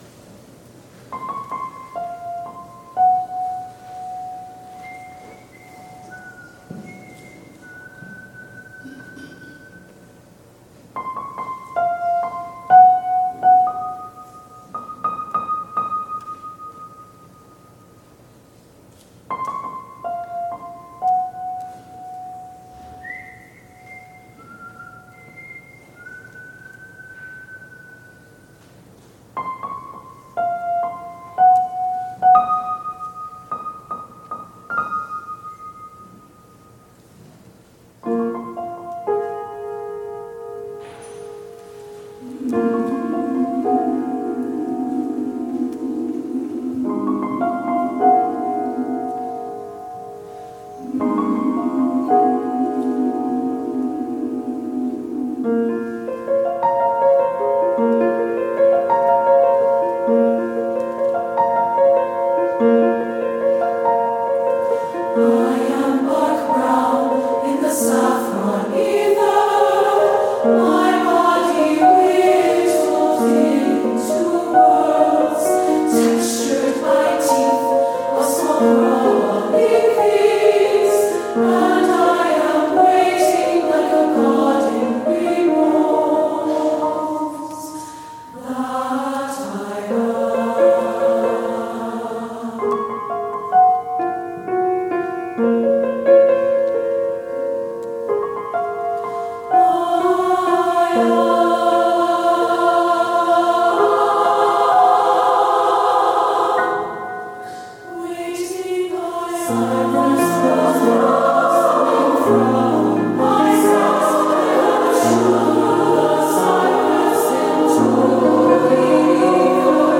for SSA div. choir and piano